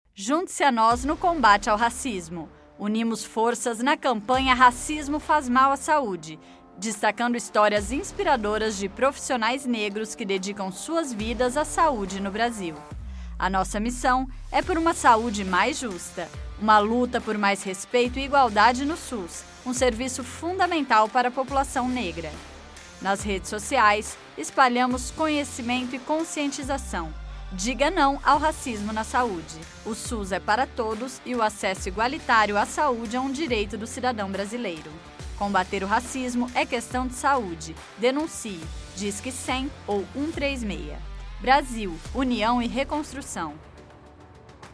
Spot - Campanha Nacional do Racismo faz mal à saúde .mp3 — Ministério da Saúde